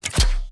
HEDZReloaded/assets/sfx/abilities/crossbow.ogg at a647edfa1339dff9cd8bd9f8648e678e7cfffe94
crossbow.ogg